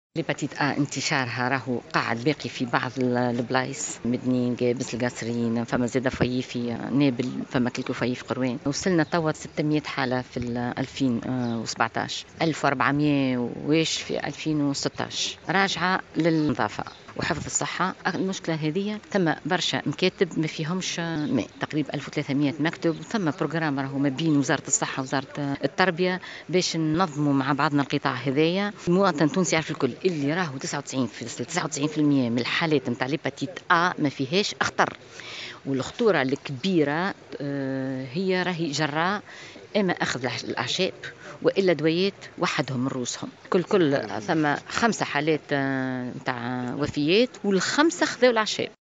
أكدت المديرة العامة للصحة نبيهة برصالي فلفول في تصريح لمراسل الجوهرة "اف ام" بالقيروان تسجيل حالات اصابة بمرض التهاب الكبد الفيروسي في عدد من المبيتات بكل من ولايات قابس ,القصرين , نابل , القيروان ومبيت في بئر علي بن خلفية من ولاية صفاقس إلى حد اليوم.